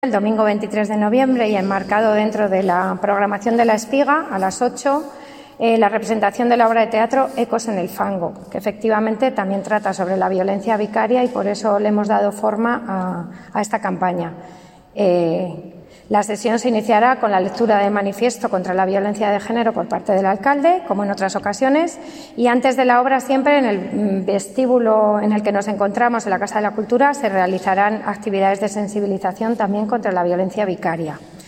Declaraciones de la concejala de Igualdad sobre los actos del 25N